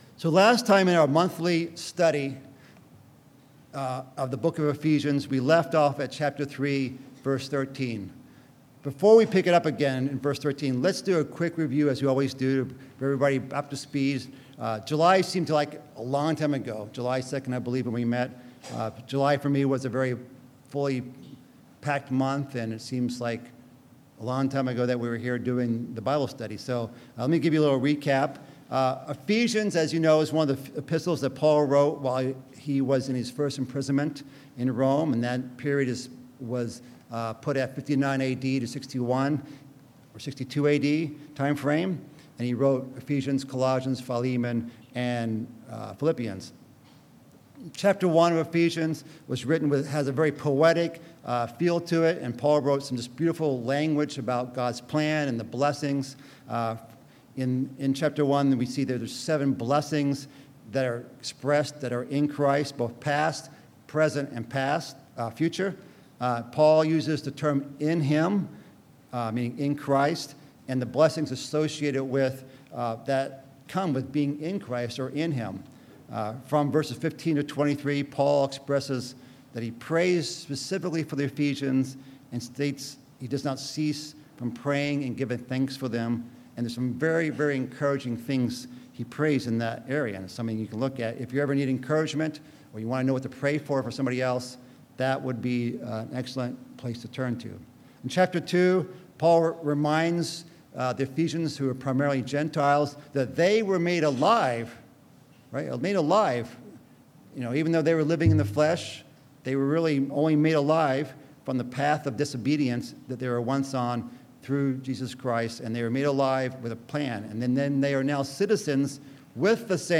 Bible Study: Ephesians